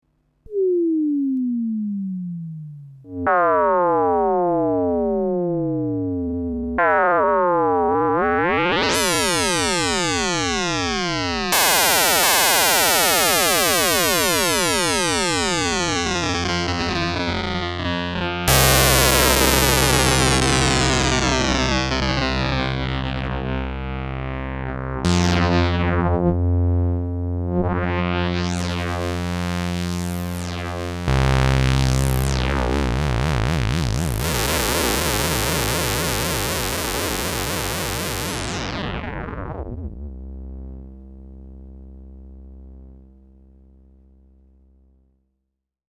Here is a quick example of the easy to do Filter-FM modulation for Moog Prodigies:
(721 kB, 128 kbps, mono).
First no FM self oscillating filter.
Then a bit FM applied.
Plenty FM applied and the cut-off moved.
At 0:17 the oscillators are faded in.
0:31 LFO applied to the oscillators. And therefor the oscillator 2 is FM-ed and modulating the filter.
The last seconds are without FM again.
Moog_Prodigy_FM_demo.mp3